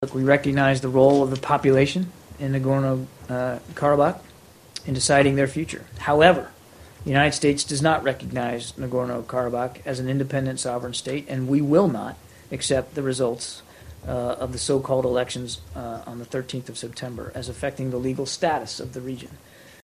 ABŞ Dövlət Departamentinin sözçüsü John Kirby sentybarın 10-da brifinqdə deyib: "Biz Dağlıq Qarabağın gələcəyinin müəyyən olunmasında Qarabağ əhalisinin rolunu tanıyırıq. Bununla belə, ABŞ Dalıq Qarabağı müstəqil dövlət kimi tanımır. Biz regionun gələcək statusunun müəyyən olunmasına təsiri baxımından sentyabrın 13-dəki seçkilərin nəticələrini də tanımırıq"